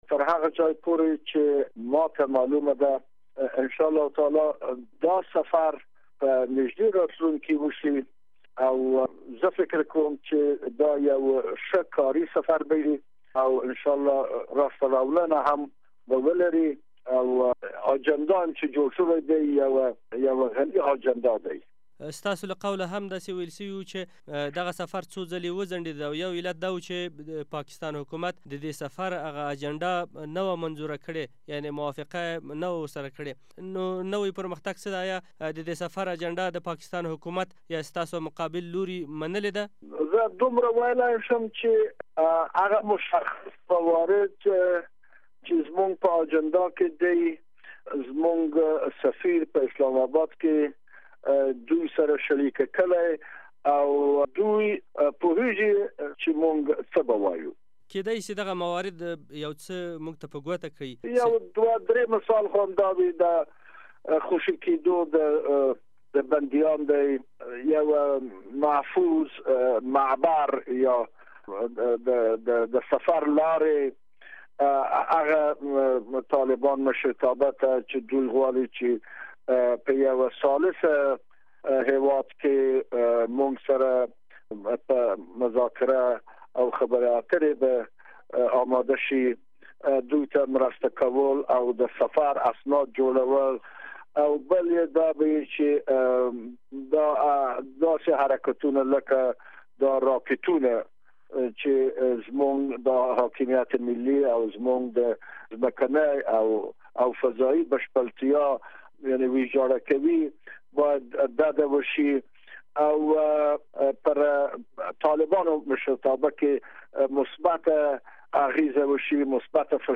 پاکستان ته د سولې د عالي شورا د سفر په اړه له اسمعیل قاسمیار سره مرکه